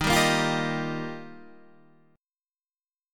Ebadd9 Chord
Listen to Ebadd9 strummed